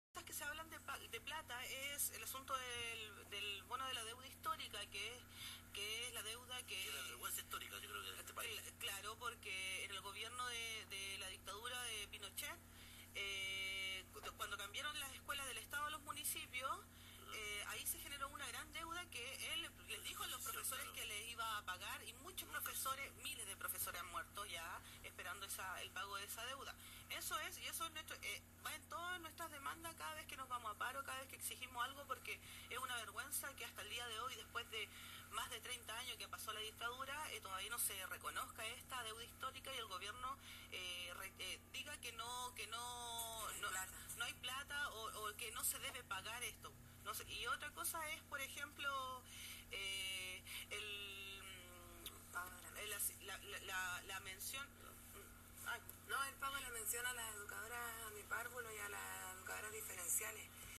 Entrevista radial a profesoras de la Agrupación Nuestra Clase a raíz del Paro docente